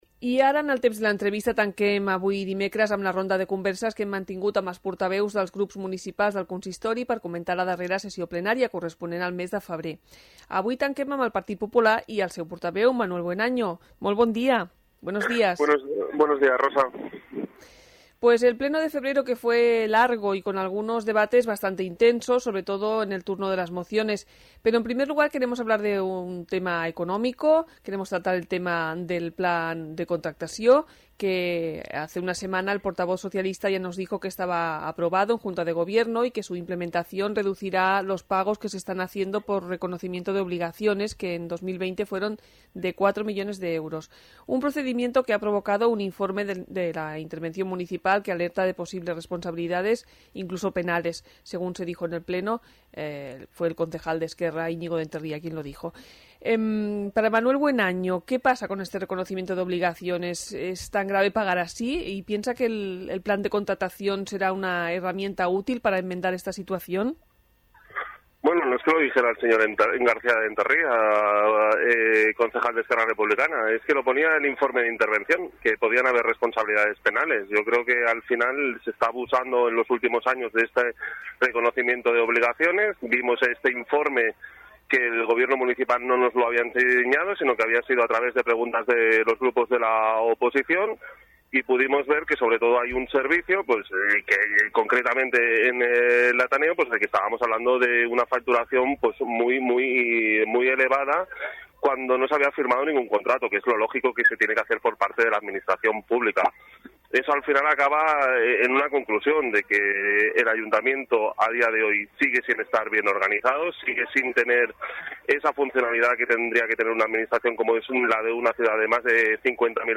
Entrevista-Manuel-Buenaño-PP-Ple-febrer.mp3